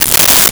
Tear Paper 08
Tear Paper 08.wav